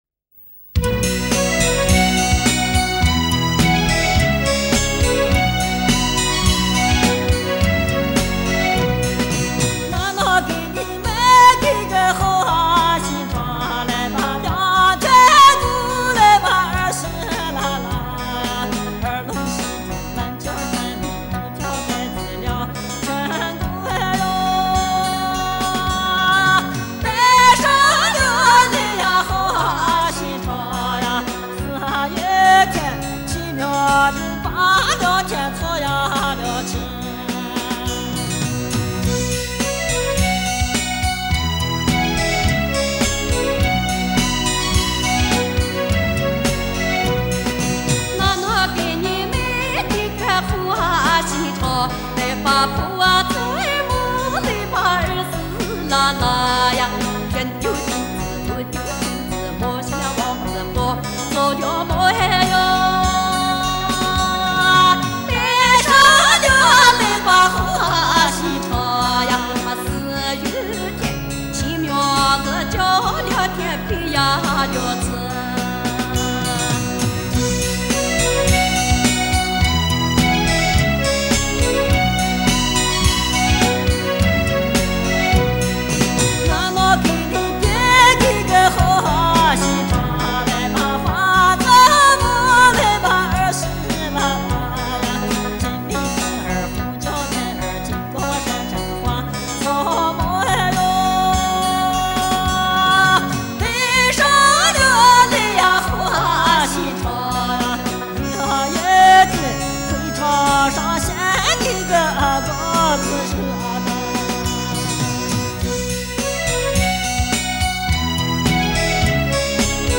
首页 > 图文板块 > 临夏花儿
青海花儿 - 好心肠.mp3